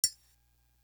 Book Of Rhymes Triangle.wav